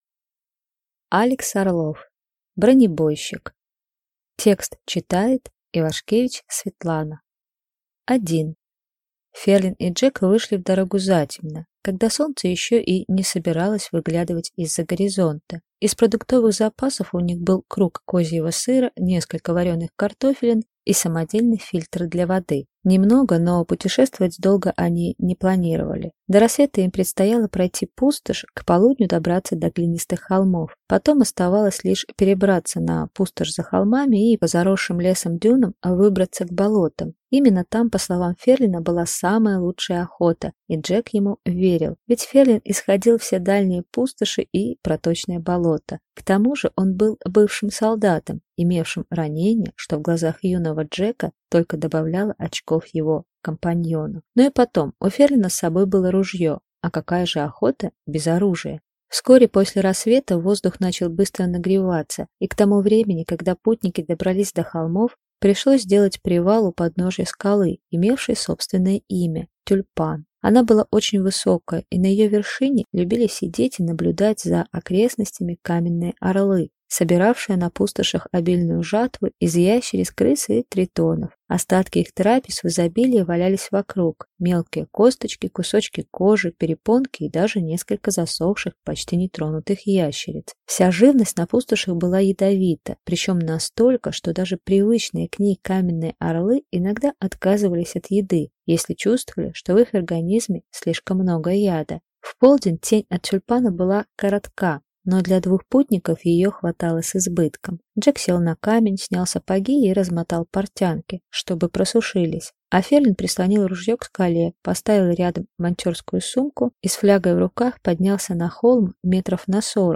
Аудиокнига Бронебойщик | Библиотека аудиокниг